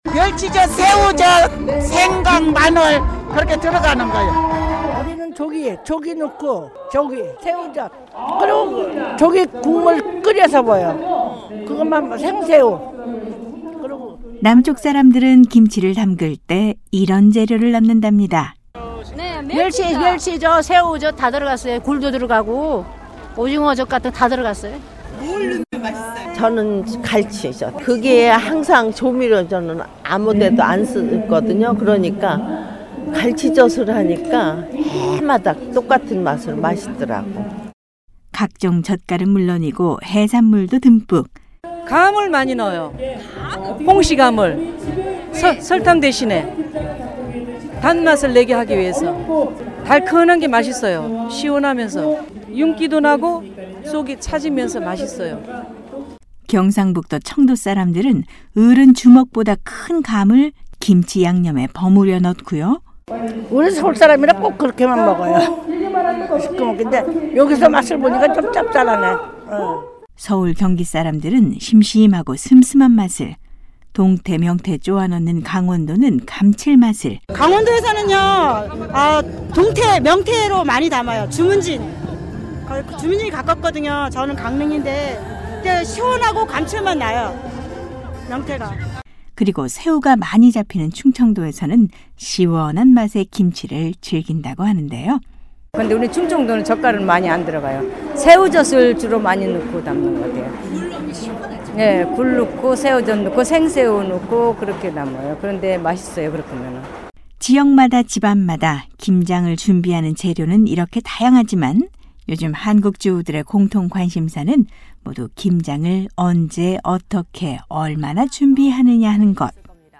서울의 주부들을 만나봤습니다.